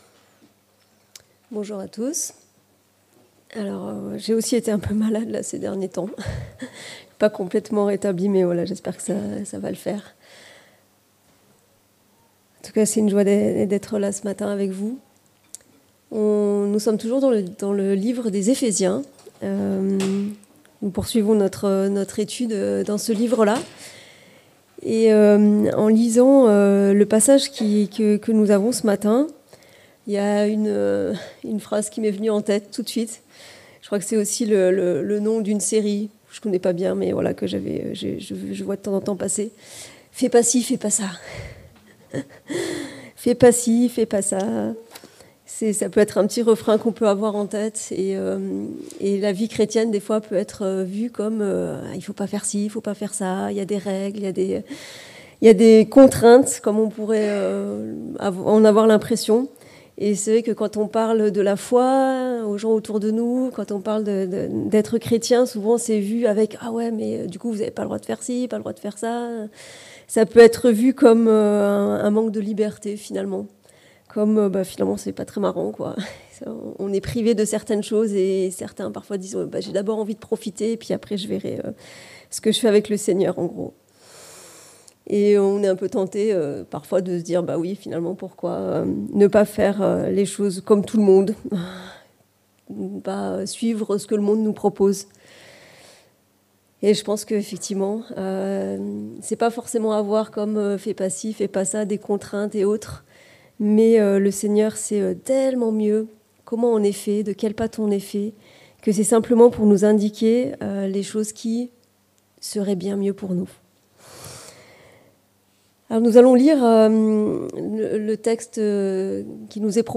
Ephésiens Prédication textuelle